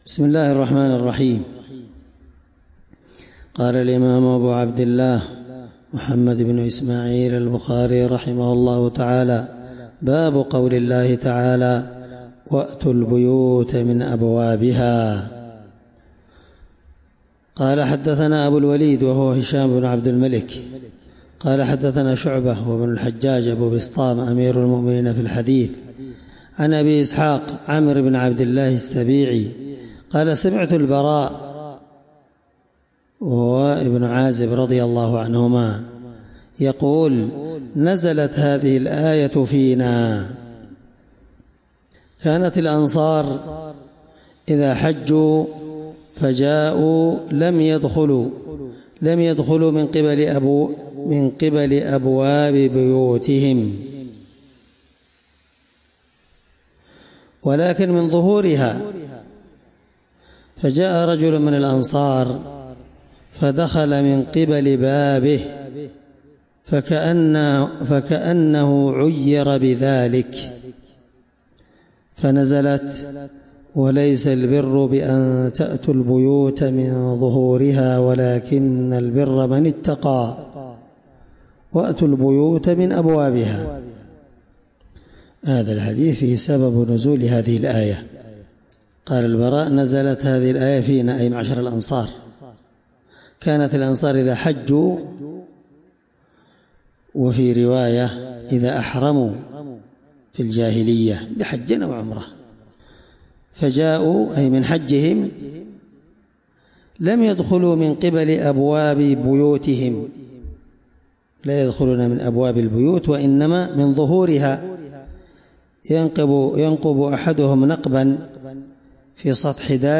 الدرس 13من شرح كتاب العمرة حديث رقم(1803)من صحيح البخاري